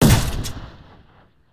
Gun_Turret1.ogg